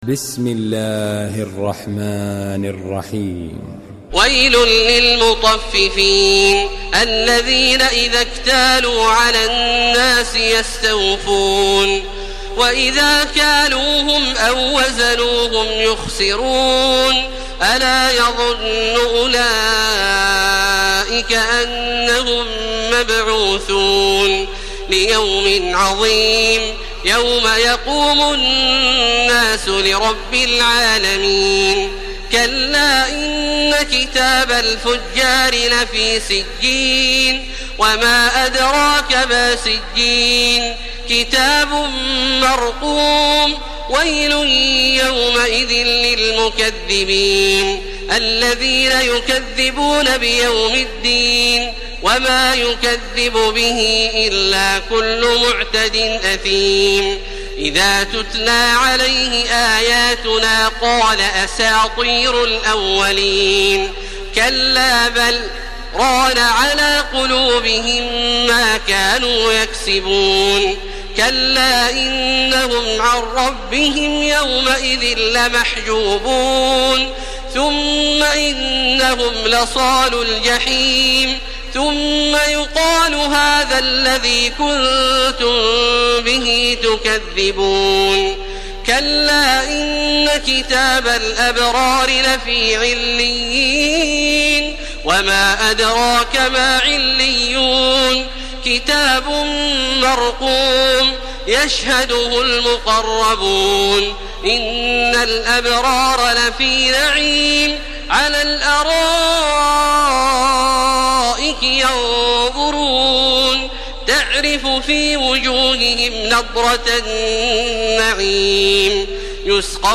Surah Müteffifin MP3 in the Voice of Makkah Taraweeh 1431 in Hafs Narration
Murattal Hafs An Asim